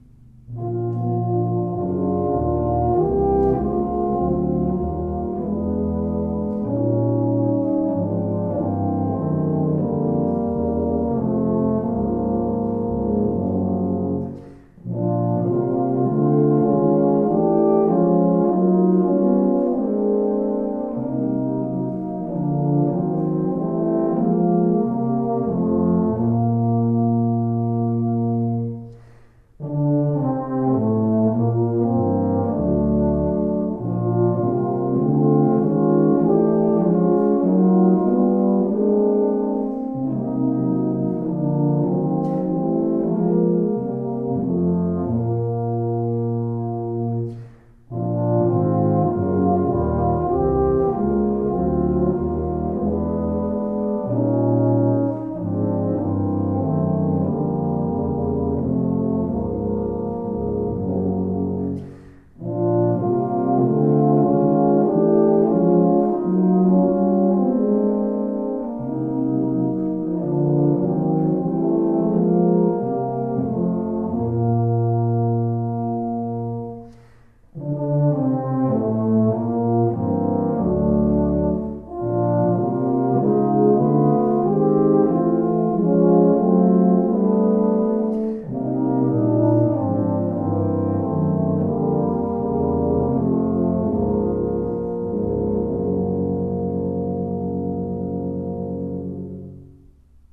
For Tuba Quartet (EETT), Composed by Traditional.